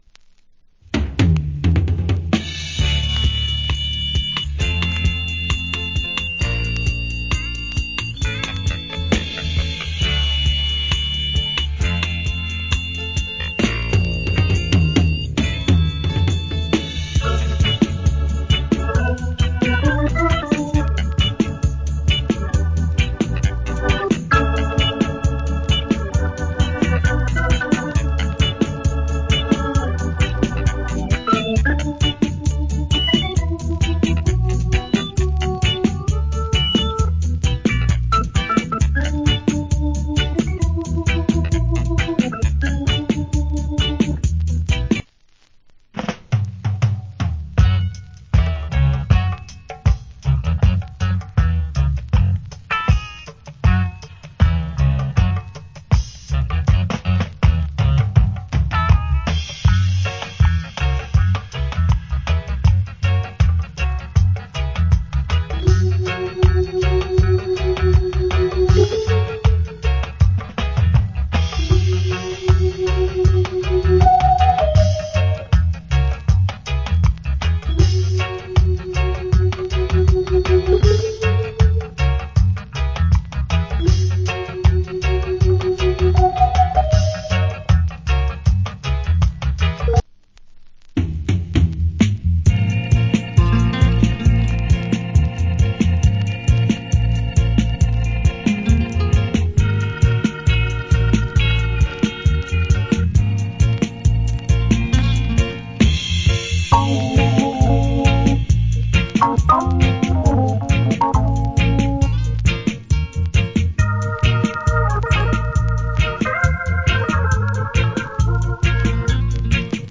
Nice Organ Reggae Inst